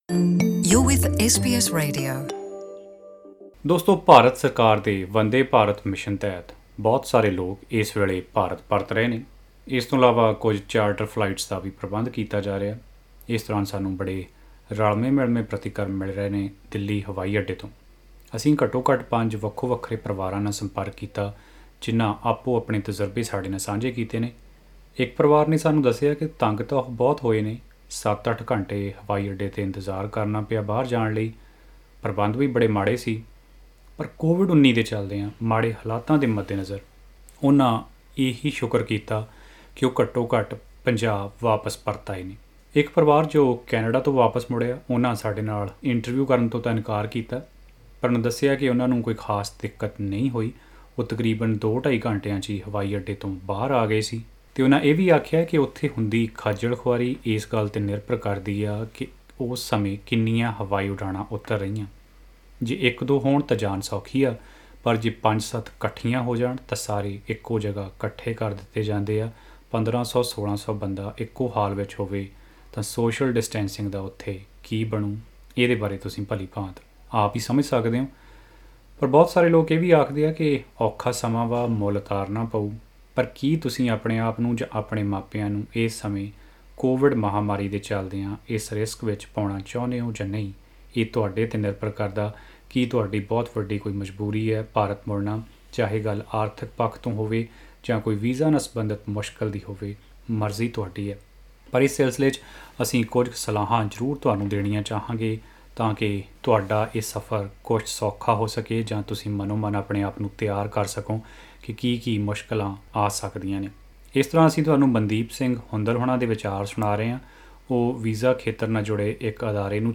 ‘Worst day of my life’: Travellers speak up about long delays at New Delhi airport